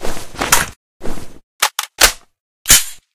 ru556_reload_empty.ogg